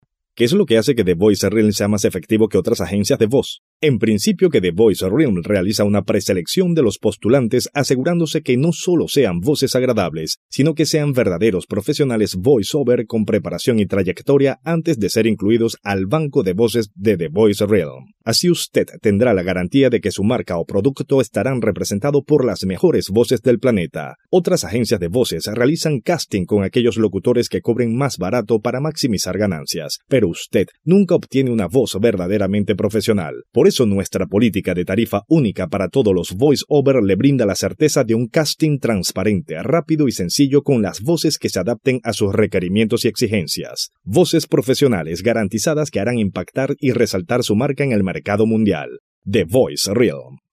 Male
Spanish (Latin American)
Adult (30-50)
Una cálida voz en tonos medios con gran versatilidad.
Main Demo